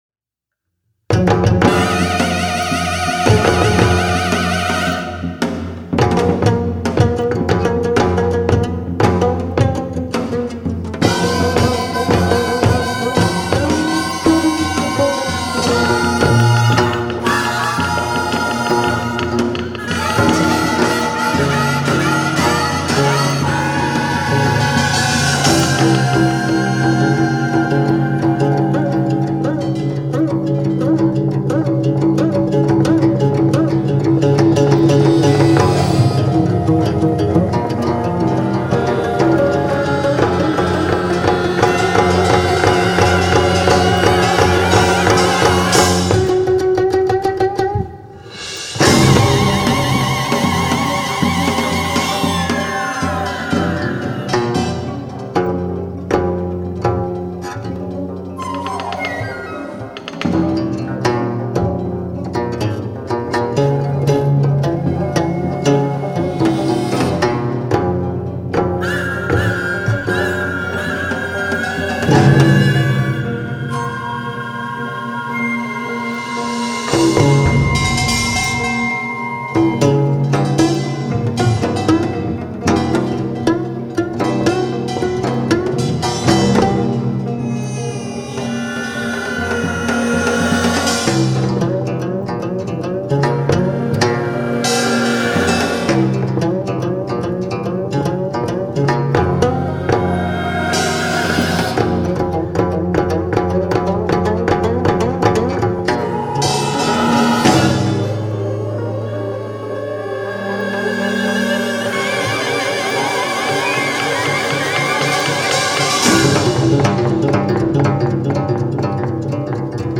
concerto for 9-string geomungo & gugak orchestra